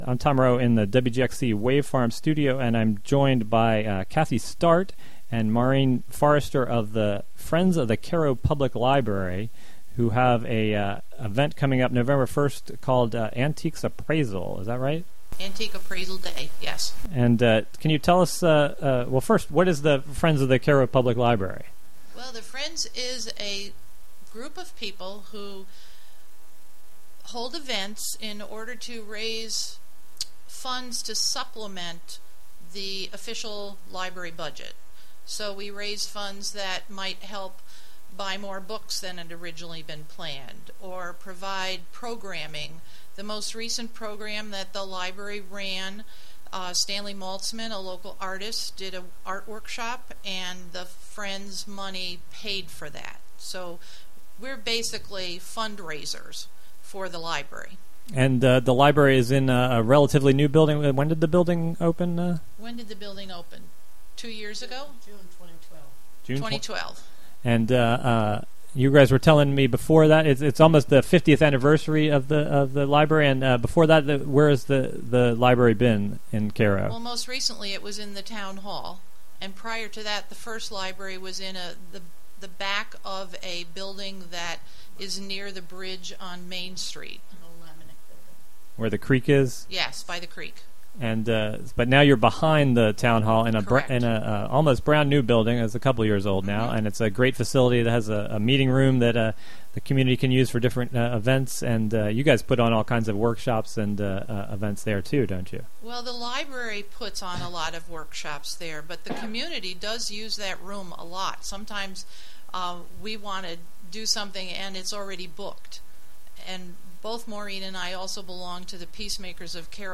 Local interviews, local news, regional events, mus...